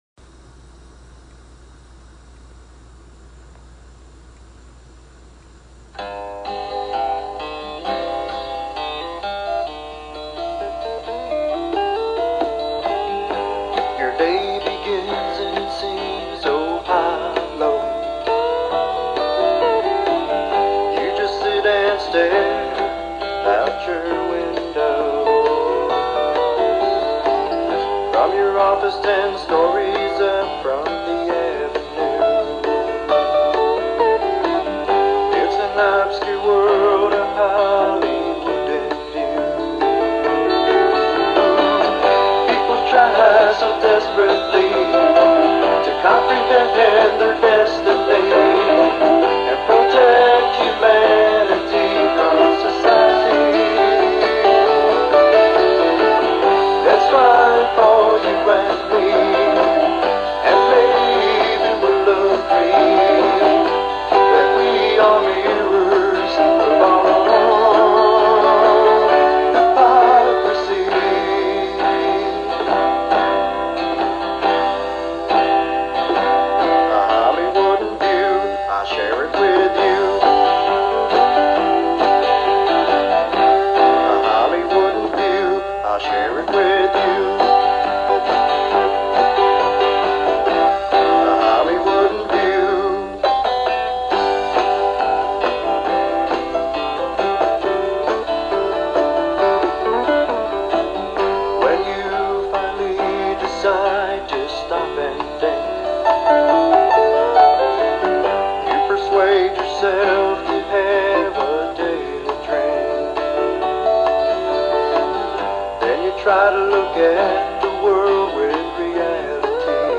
This devotional sermon encourages self-awareness and a renewed perspective on life's challenges.